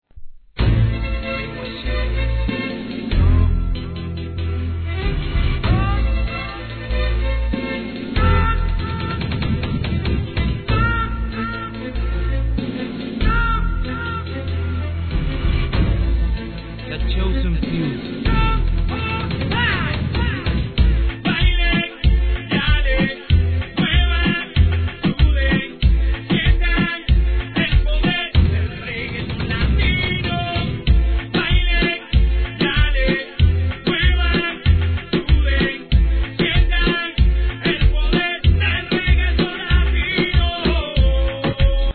HIP HOP/R&B
■REGGAETON No. タイトル アーティスト 試聴 1.